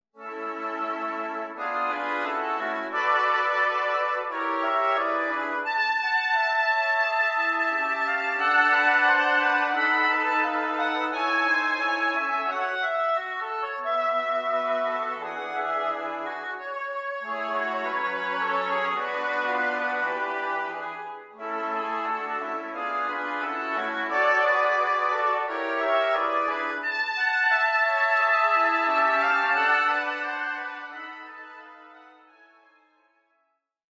show tune